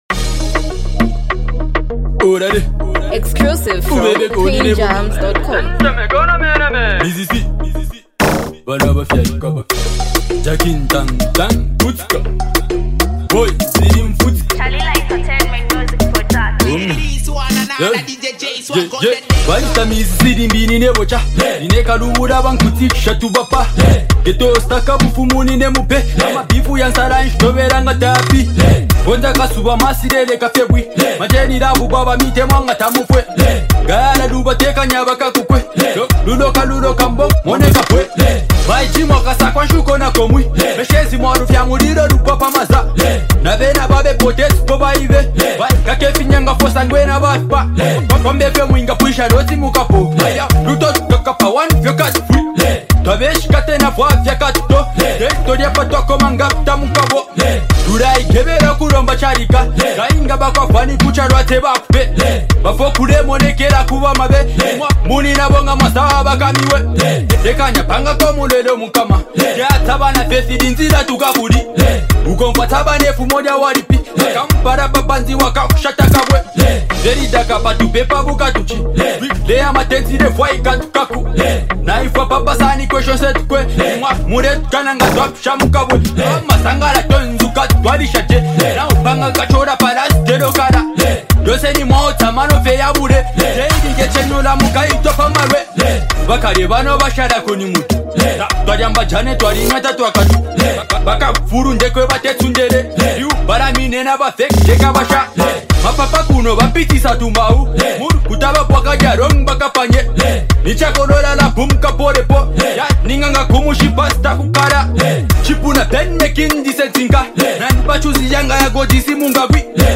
a smooth and expressive song